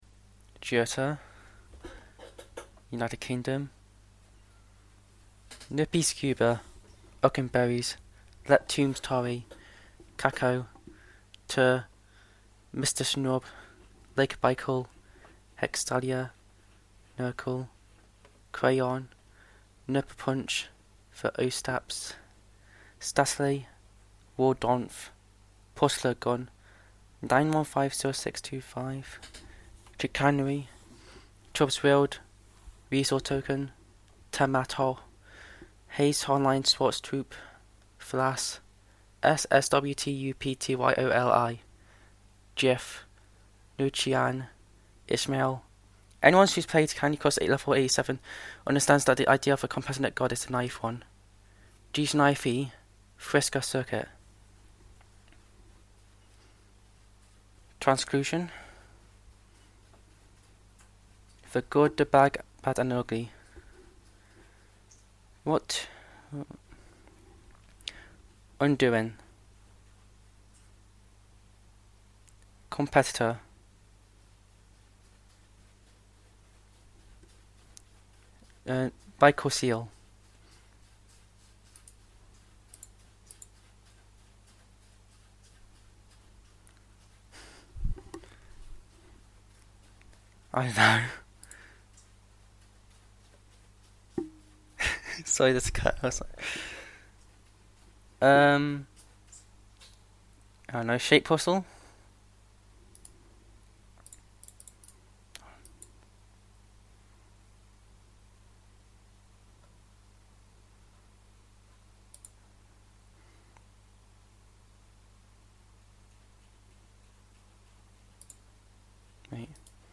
Recordings of ourselves answering those questions.
im so sorry this sucks :sob: i barely speak so pardon my slight stuttering